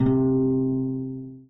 guitar_c.ogg